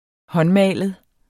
Udtale [ ˈhʌnˌmæˀləð ]